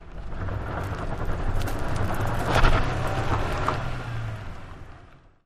Tires on Dirt
Car Tires Gritty; Peel Outs, On Board Perspective 5x